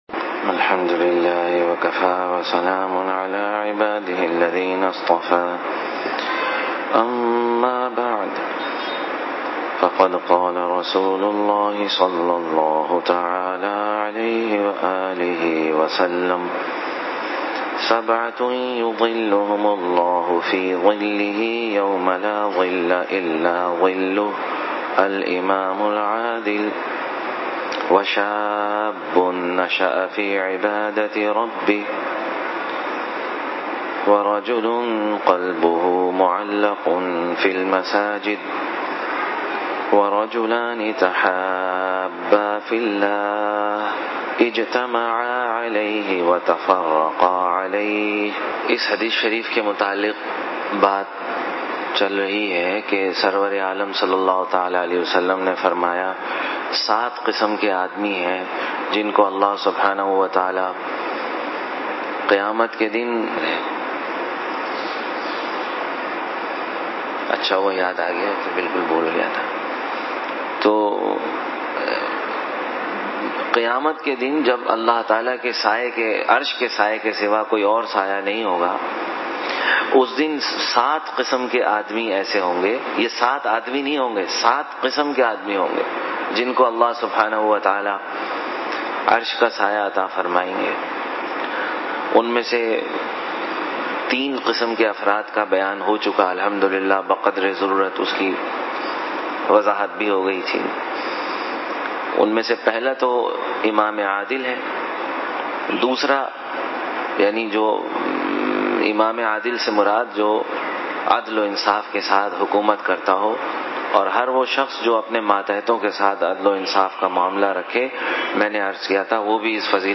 Ramadan Beyan